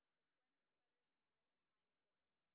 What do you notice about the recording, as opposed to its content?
sp29_street_snr0.wav